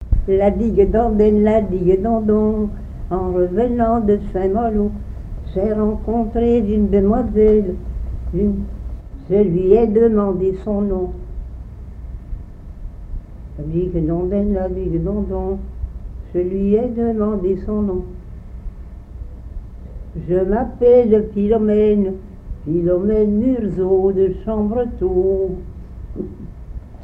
répertoire de chansons
Pièce musicale inédite